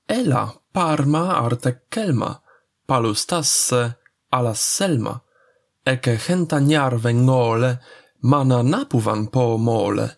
slow version